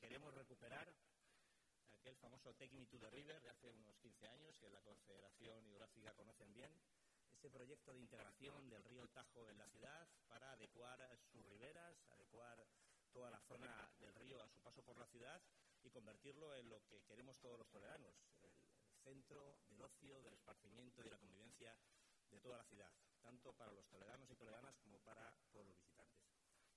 Cortes de Voz
el-alcalde-de-toledo-participa-en-el-iv-congreso-iberico-de-restauracion-fluvial-4.mp3